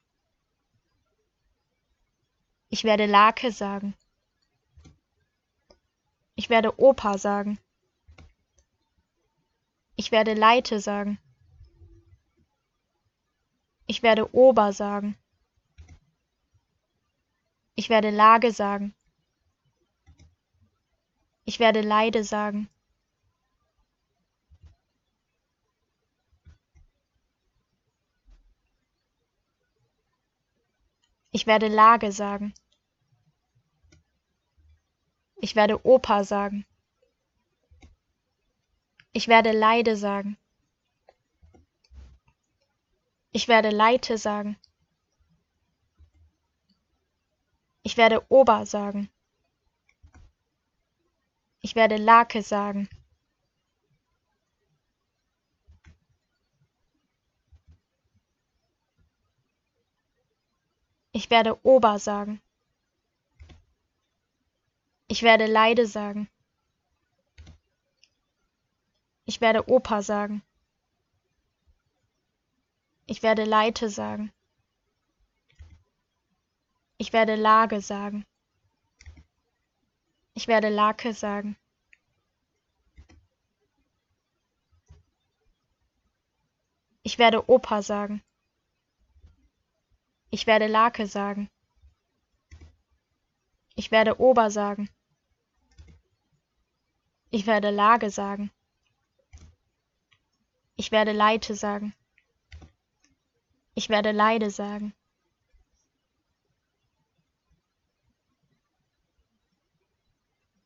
Plosive_DE.wav